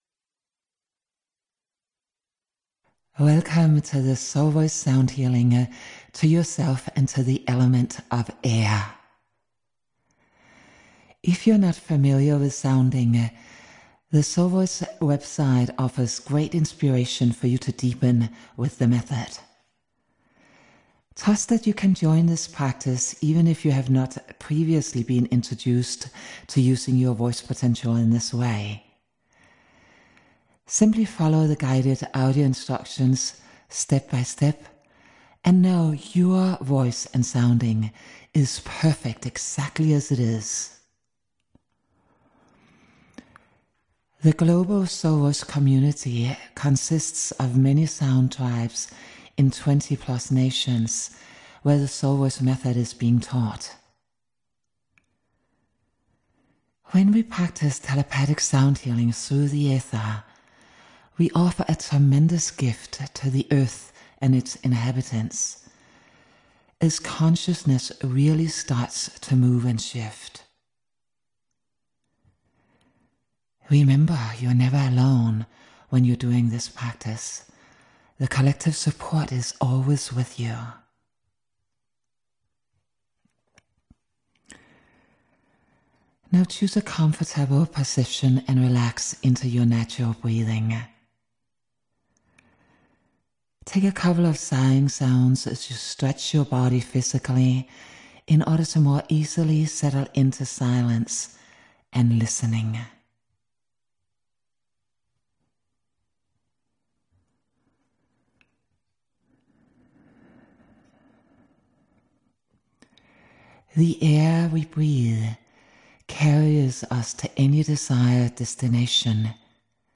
JOIN US at any time during the month in a guided Sounding Meditation that is both a Self Healing Journey as well as a Telepathic Sound Healing.